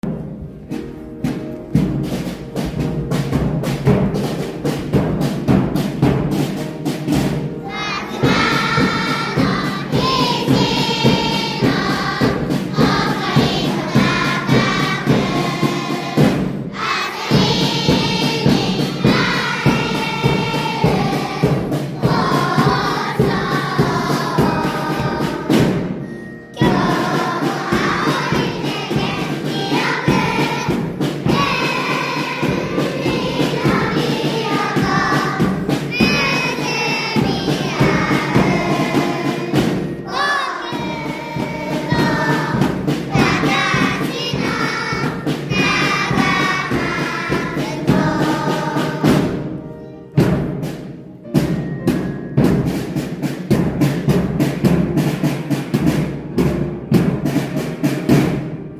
押すと，全校朝会での校歌が流れます。